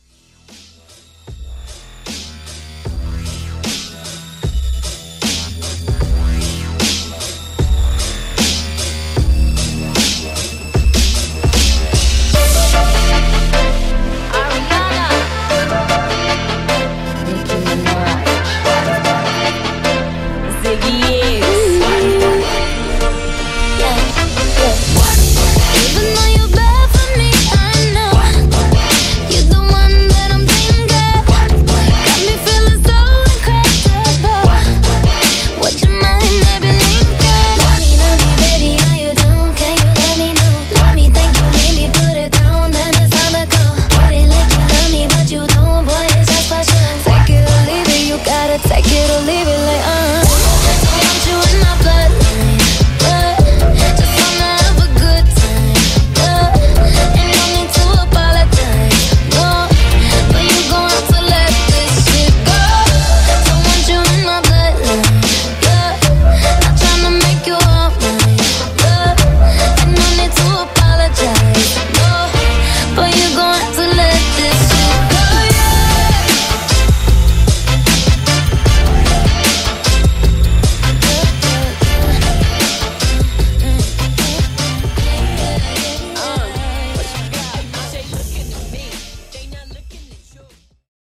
Pop Dubstep Edit